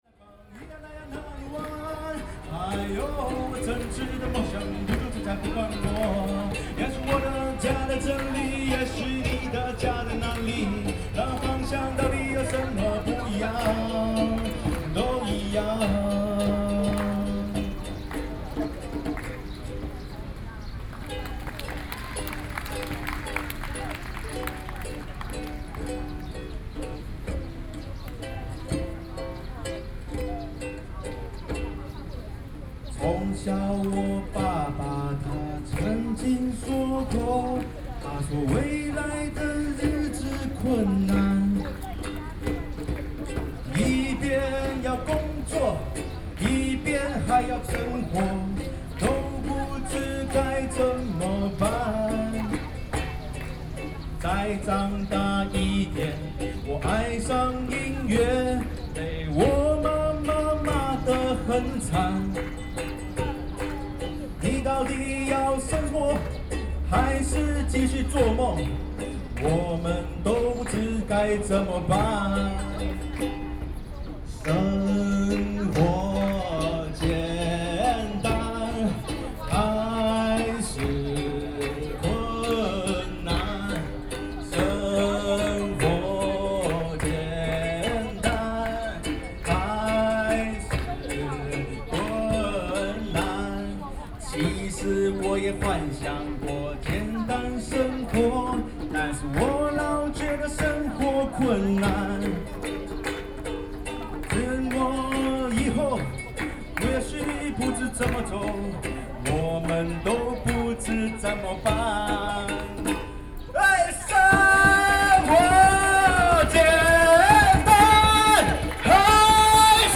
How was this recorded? Chiang Kai-shek Memorial Hall,Taipei - Band performances Best with Headphone